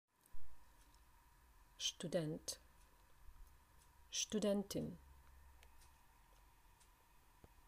Klikom na strelicu čućete izgovor svake reči koja označava profesiju.